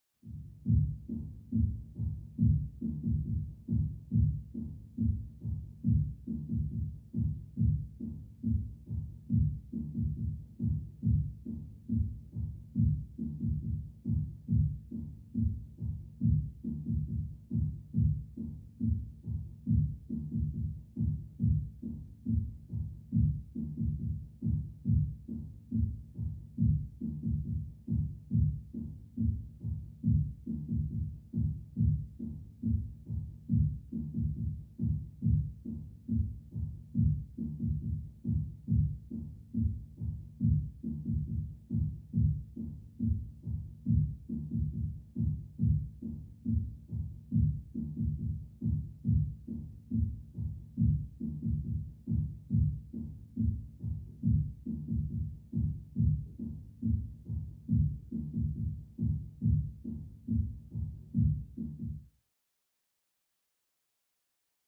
Music; Electronic Dance Beat, Through Thick Wall.